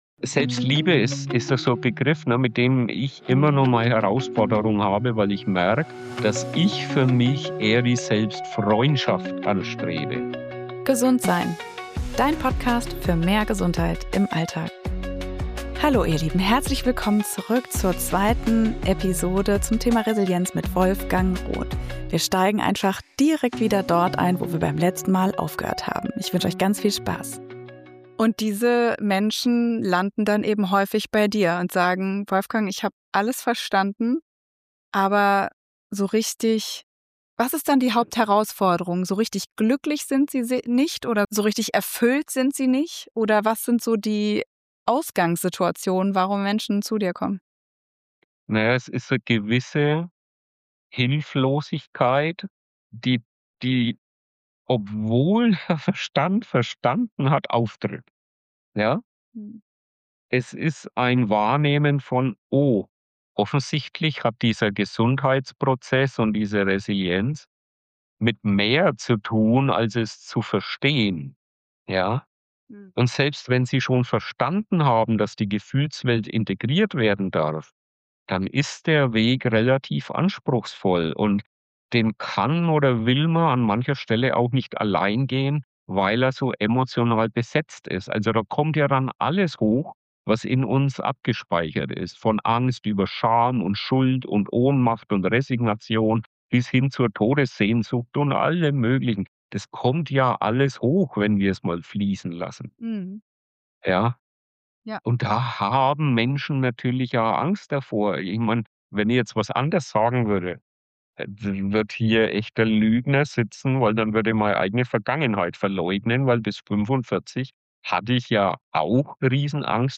Ein tiefgründiges Gespräch über die „Midlife Chance“, den Mut zur Selbstfreundschaft und die heilende Wirkung fließender Emotionen.